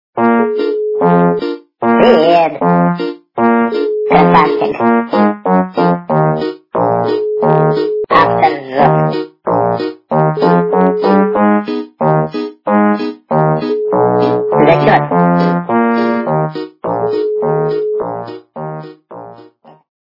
» Звуки » Люди фразы » Голос - Привееет
При прослушивании Голос - Привееет качество понижено и присутствуют гудки.